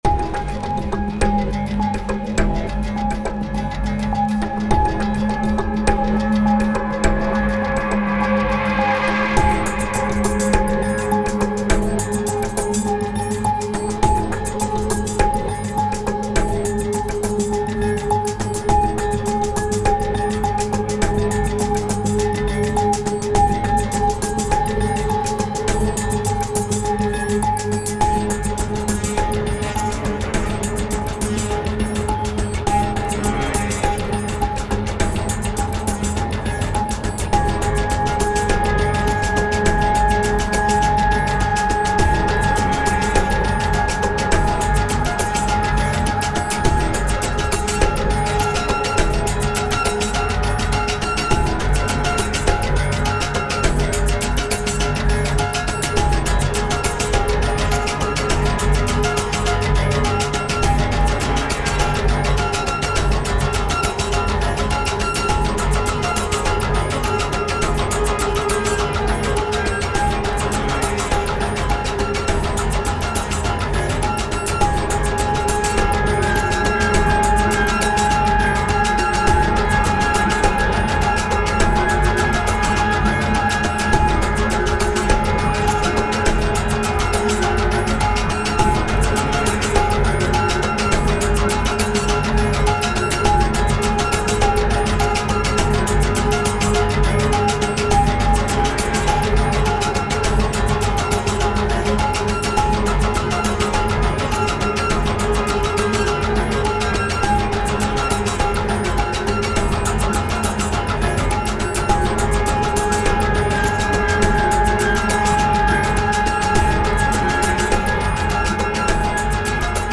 タグ: ドキドキ/緊張感 音少なめ/シンプル コメント: 緊張感が漂う危険なミッションをイメージしたBGM。